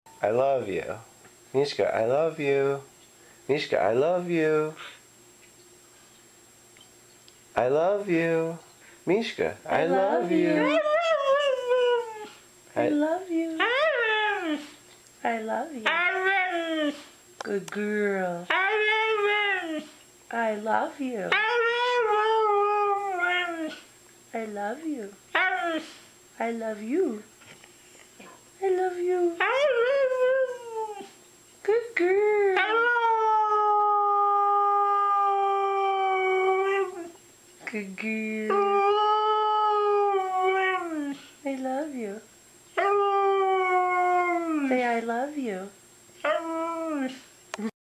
Part 42 | Husky Dog Sound Effects Free Download